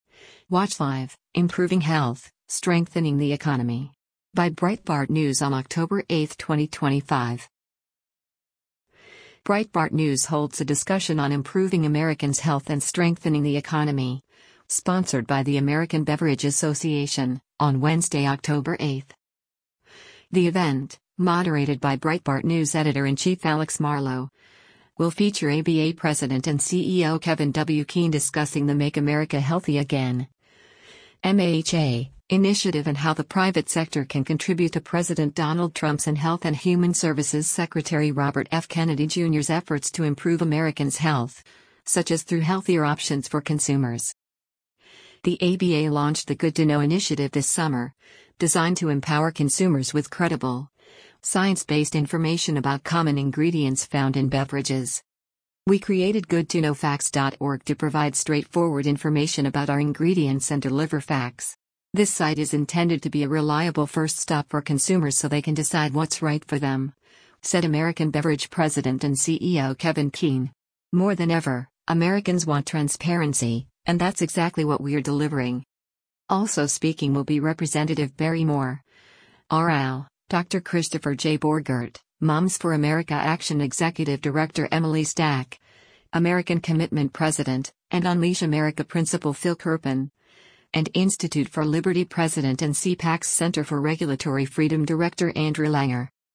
Breitbart News holds a discussion on improving Americans’ health and strengthening the economy, sponsored by the American Beverage Association, on Wednesday, October 8.